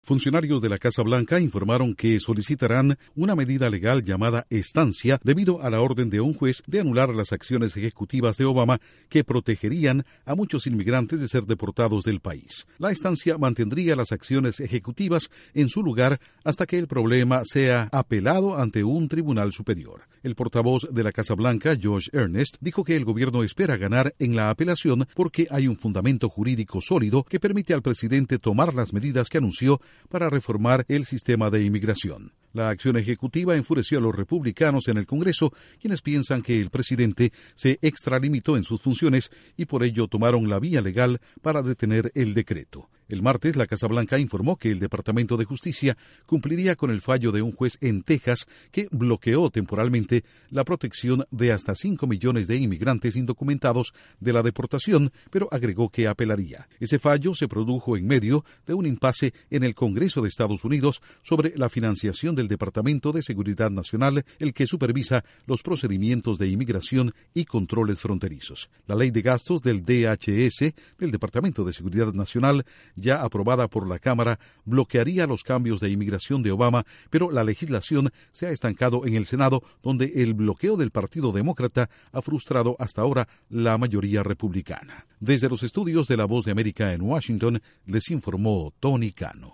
La Casa Blanca informó que este lunes presentará una apelación por la decisión de un juez de Texas de suspender la orden ejecutiva de Obama sobre inmigración. Informa desde los estudios de la Voz de América en Washington